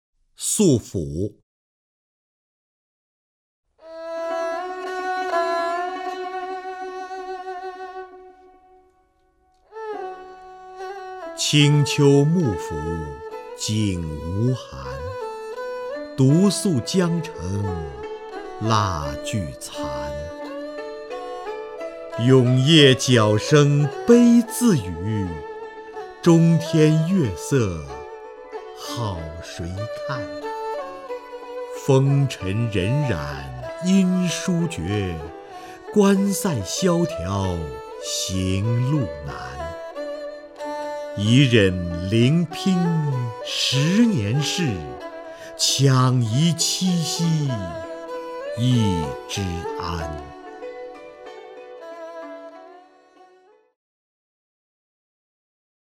瞿弦和朗诵：《宿府》(（唐）杜甫)
SuFu_DuFu(QuXianHe).mp3